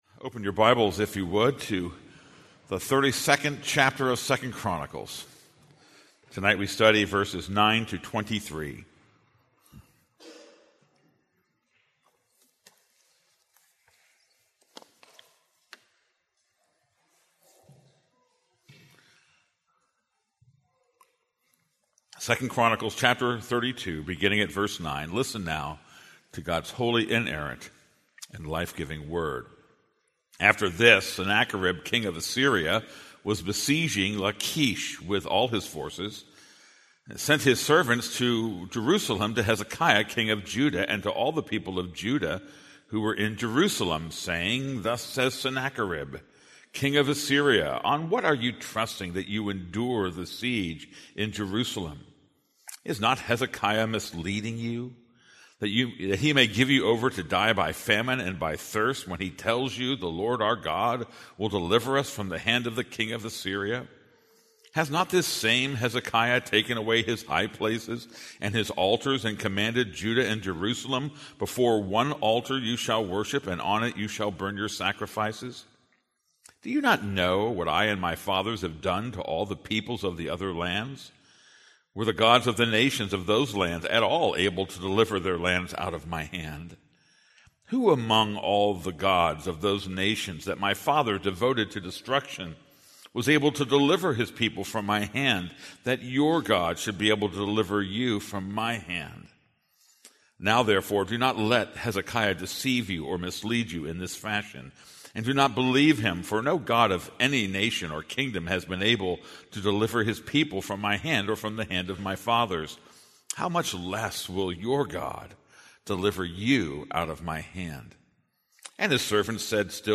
This is a sermon on 2 Chronicles 32:9-23.